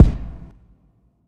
Medicated Kick 5.wav